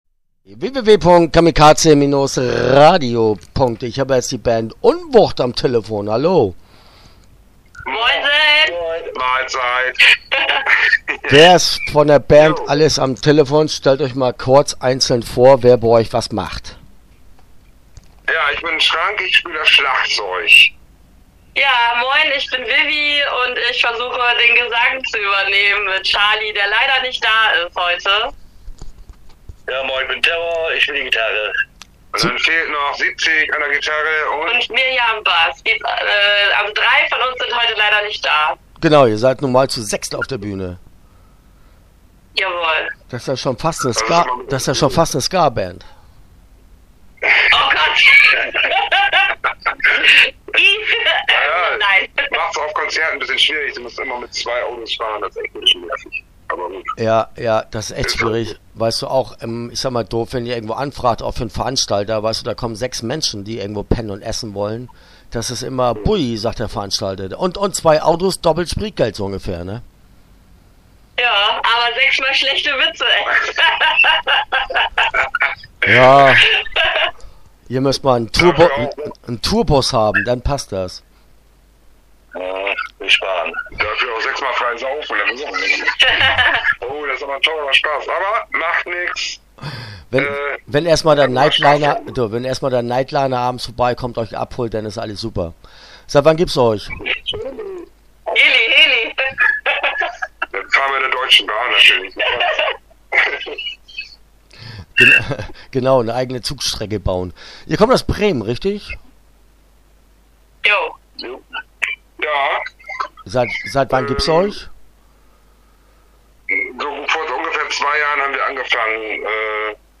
Unwucht - Interview Teil 1 (12:10)